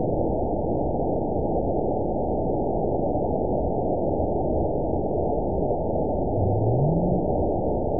event 920403 date 03/23/24 time 21:31:36 GMT (1 month ago) score 9.61 location TSS-AB05 detected by nrw target species NRW annotations +NRW Spectrogram: Frequency (kHz) vs. Time (s) audio not available .wav